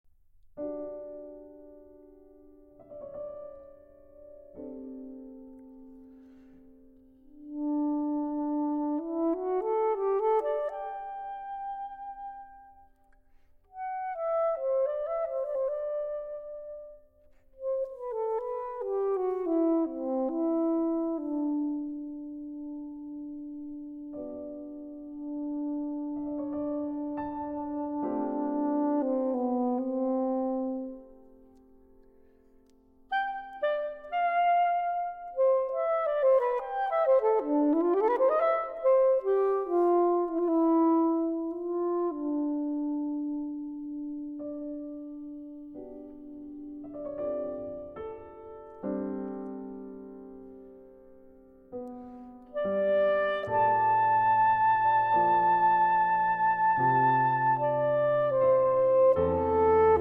Saxophone
Piano